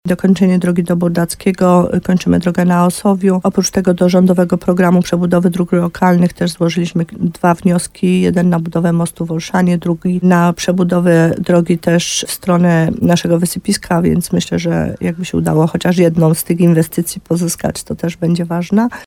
To część inwestycji, jakie zamierza w 2025 roku zrealizować gmina Podegrodzie. Na liście inwestycji są też remonty i wyposażenie świetlic środowiskowych, czy remonty i budowa dróg – mówi wójt gminy Podegrodzie, Małgorzata Gromala.